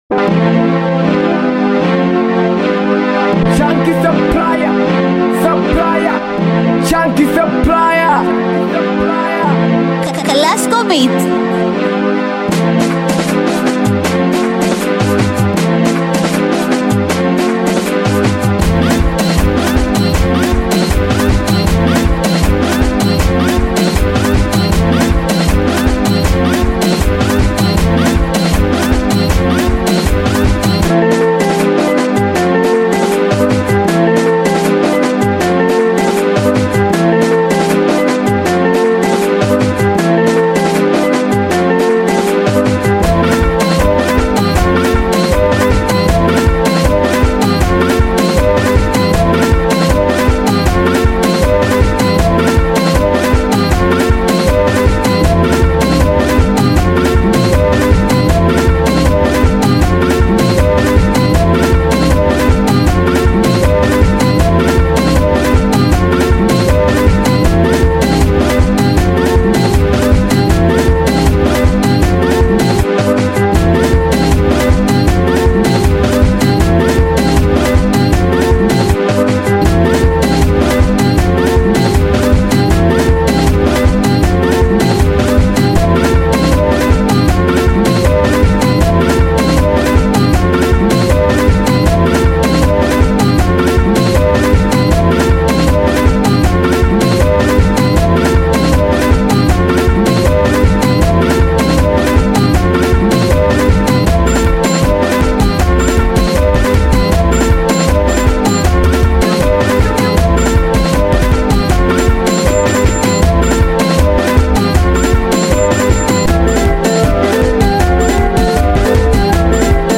BITI SINGELI SINGELI SINGELI BEAT